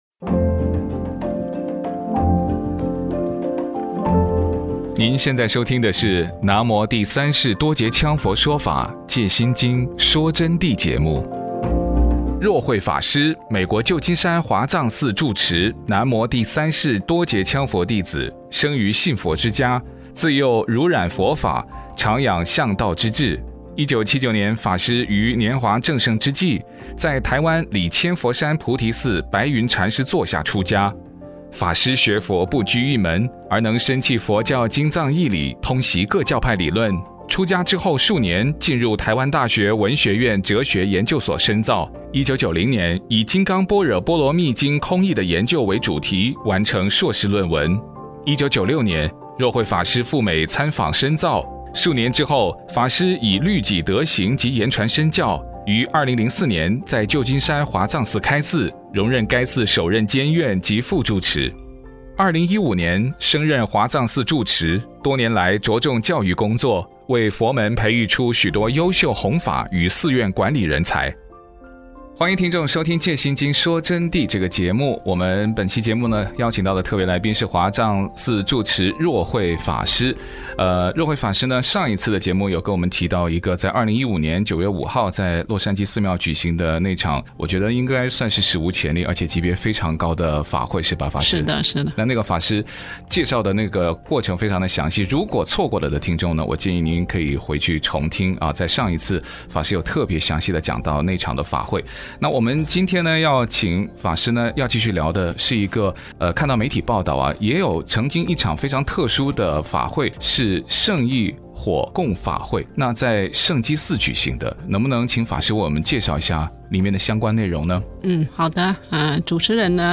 佛弟子访谈（二十二）胜义火供法会的实况与真正生死自由的含义